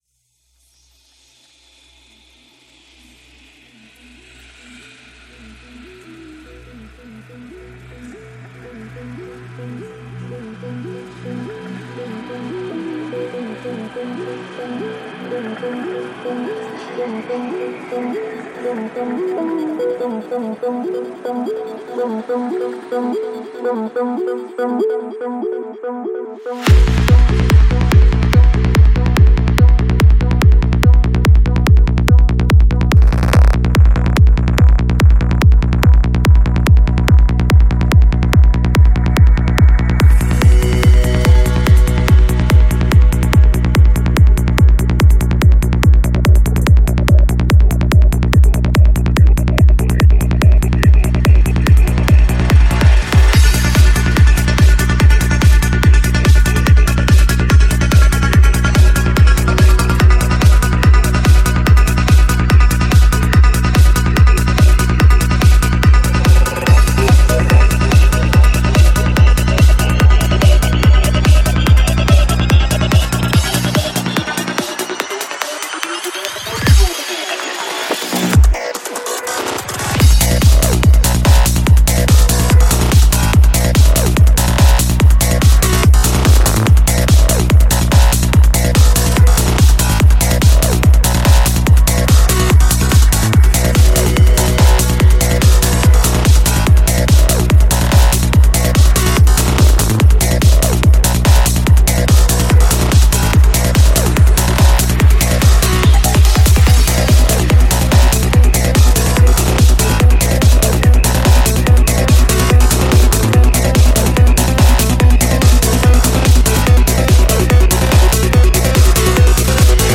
Жанр: Electro
Psy-Trance